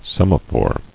[sem·a·phore]